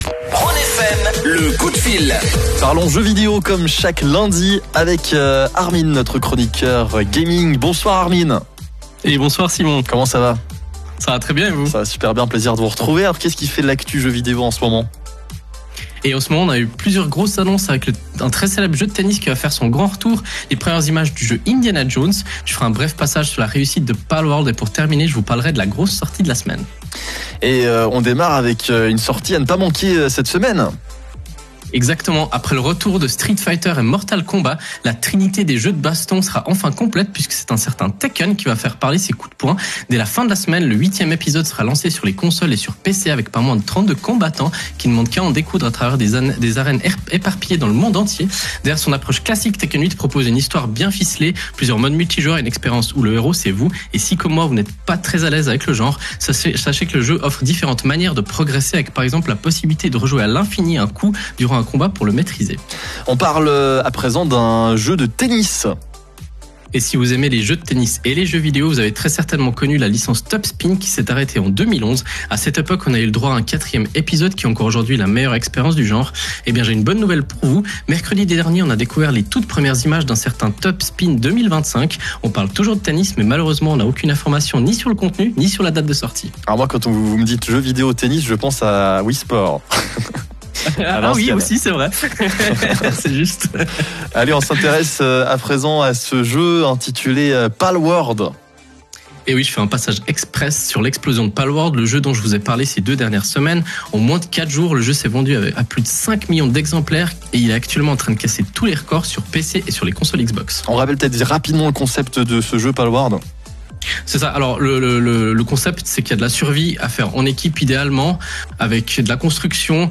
Nouveau lundi, nouvelle chronique sur la radio Rhône FM.